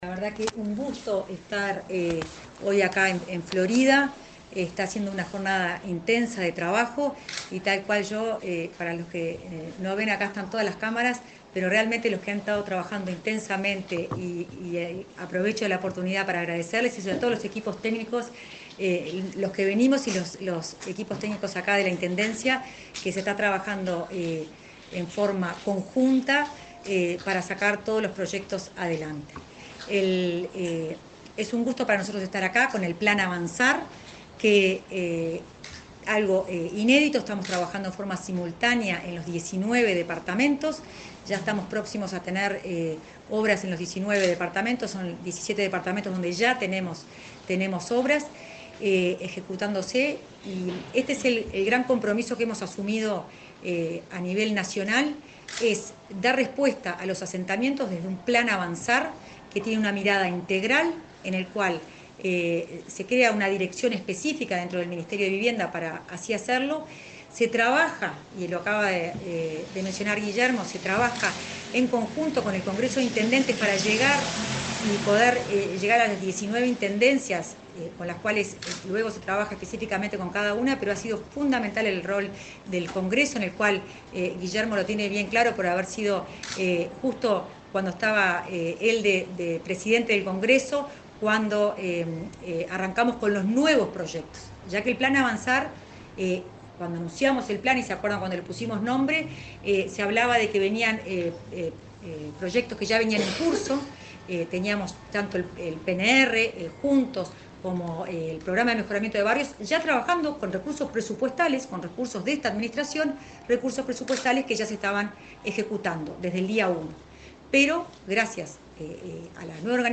Palabras de la directora de Integración Social y Urbana del Ministerio de Vivienda, Florencia Arbeleche
Este viernes 19, la directora de Integración Social y Urbana del Ministerio de Vivienda, Florencia Arbeleche, efectuó declaraciones a medios periodísticos en la Intendencia de Florida, donde realizó una revisión de acuerdos estratégicos con las autoridades locales y recorrió obras del plan Avanzar.